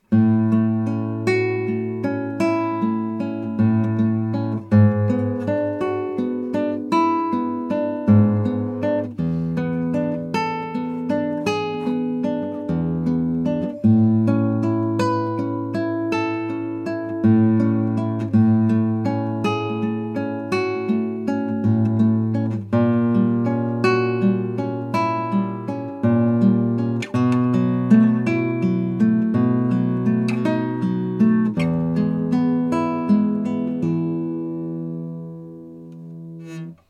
#12 Engleman Spruce and Indian Rosewood
Number 12 is a bright and powerful large sized Classical guitar with the following characteristics:
Here’s a link to a few sound clips played by myself on this instrument (sorry I’m not a better player!) Recorded on a cheap USB microphone onto Audacity on my laptop without any equalization or modification.